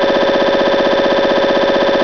'エンジンノイズの音源
EngineNoise.wav